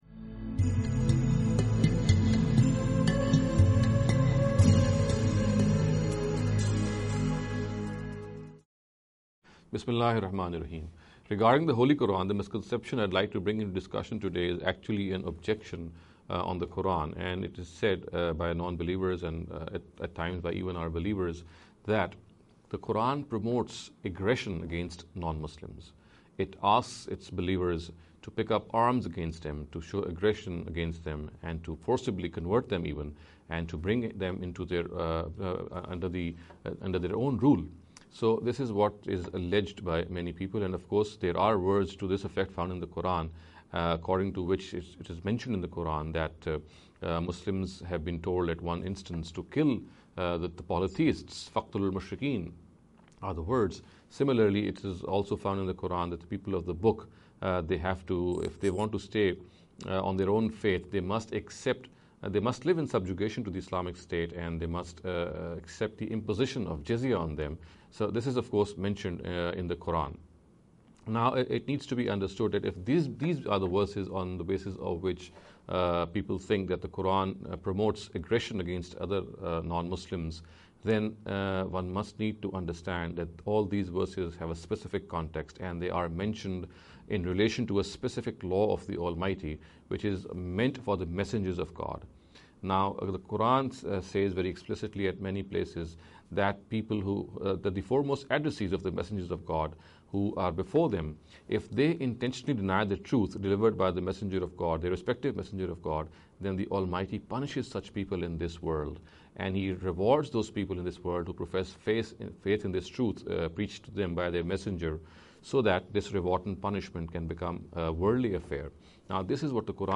This lecture series will deal with some misconception regarding the Holy Qur’an.